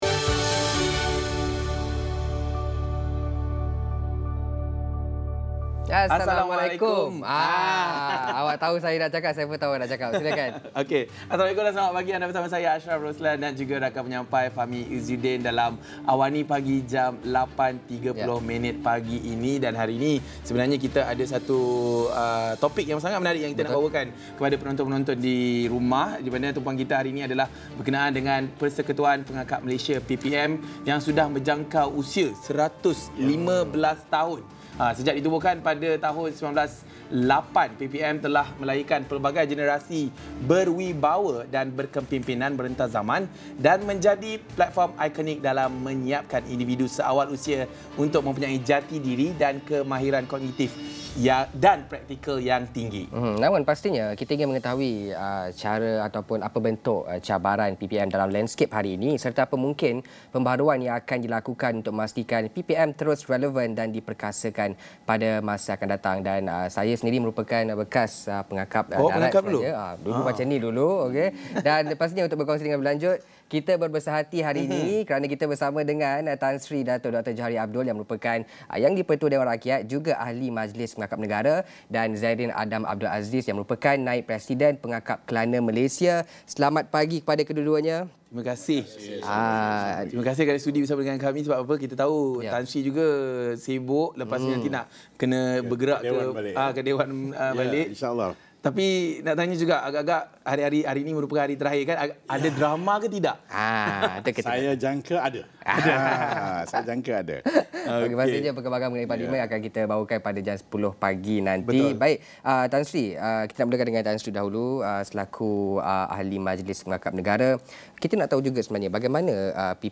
Diskusi 8.30 pagi ini bersama Yang di-Pertua Dewan Rakyat